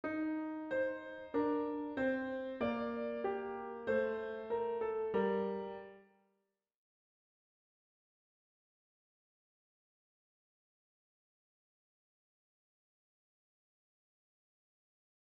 Voici les deux voix en même temps.